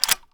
Reloading_begin0012.ogg